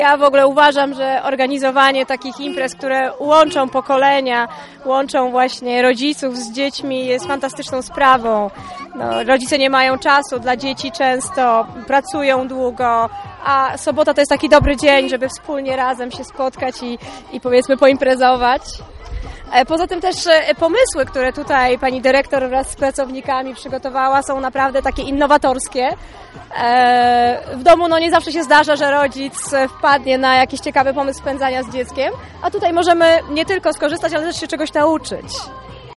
rodzic.mp3